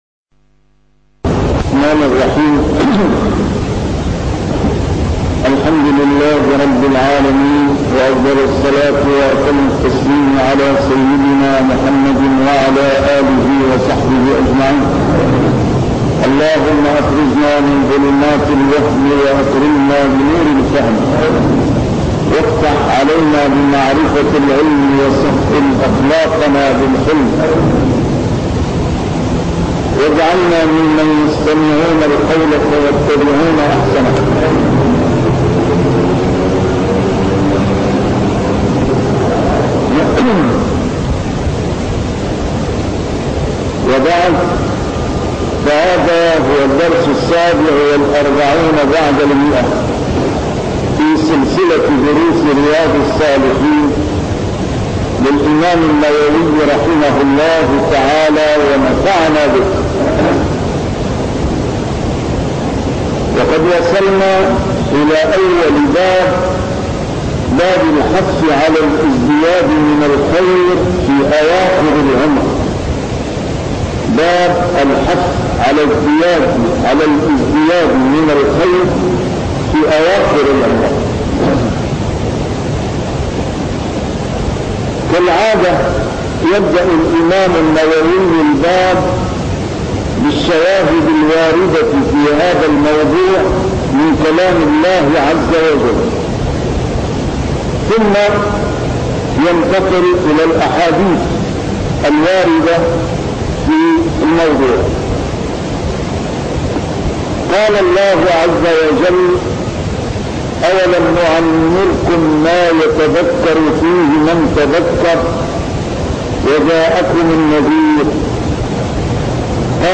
A MARTYR SCHOLAR: IMAM MUHAMMAD SAEED RAMADAN AL-BOUTI - الدروس العلمية - شرح كتاب رياض الصالحين - 147- شرح رياض الصالحين: الازدياد من الخير